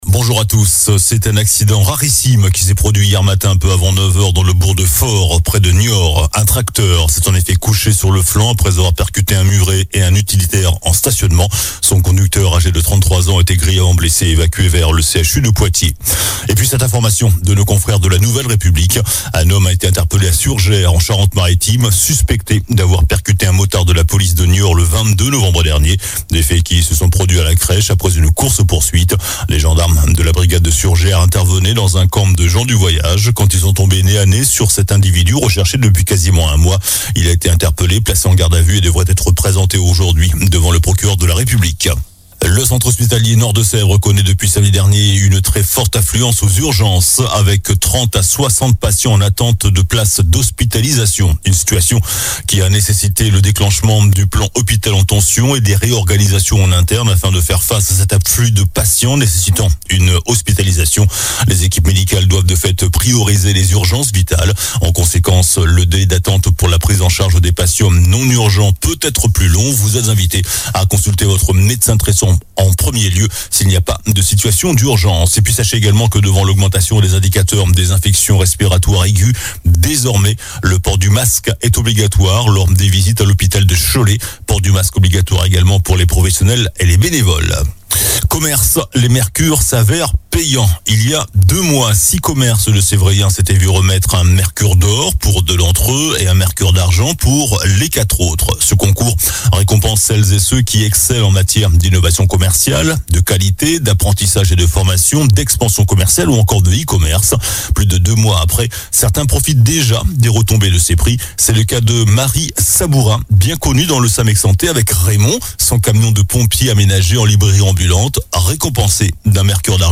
Journal du samedi 16 décembre